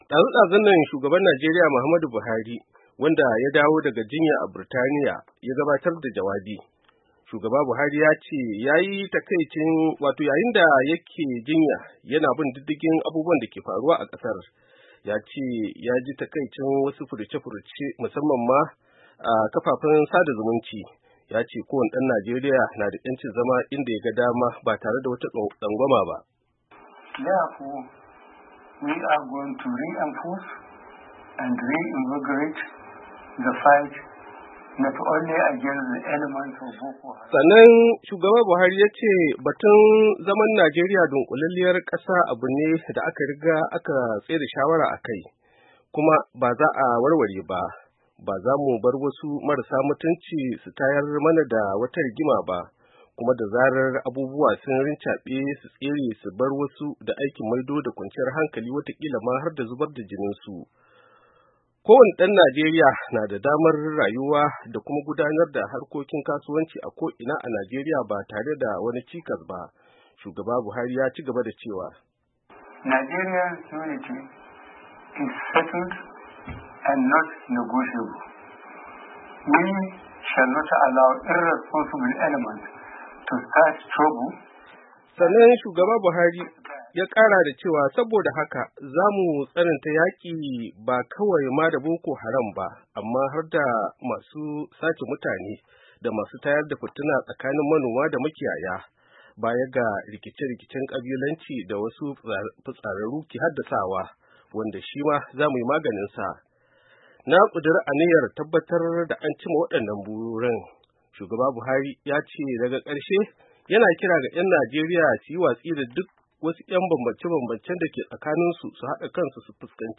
Shugaba Buhari Yayi Wa 'Yan Kasa jawabi